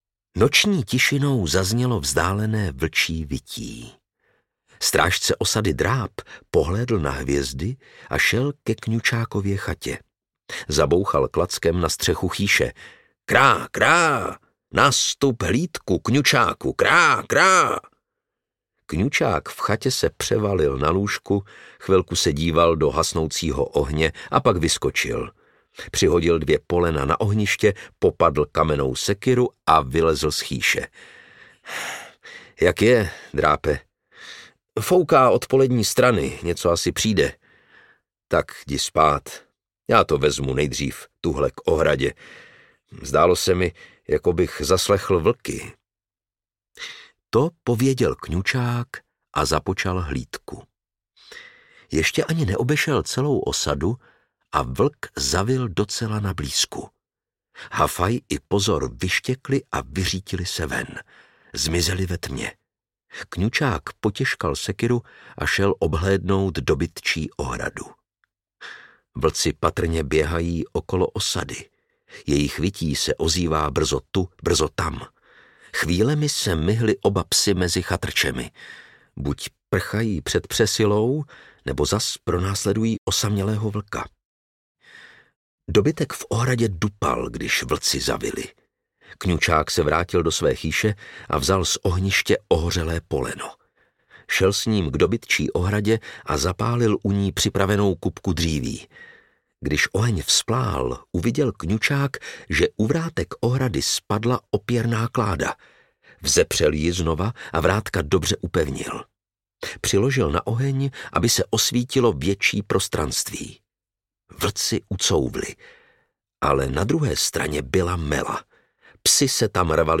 Osada havranů audiokniha
Ukázka z knihy
• InterpretLukáš Hlavica